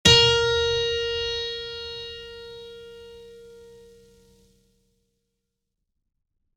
piano-sounds-dev